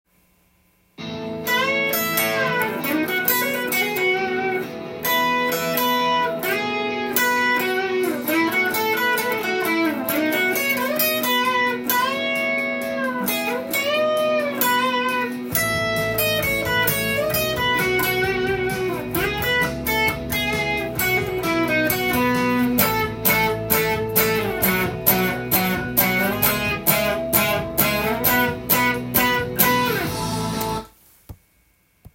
コード進行意識したＴＡＢ譜
音源に合わせて譜面通り弾いてみました